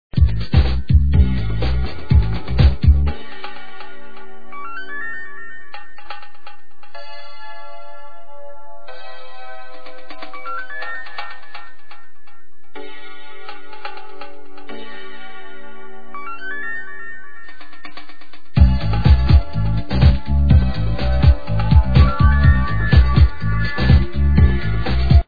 Progressive House...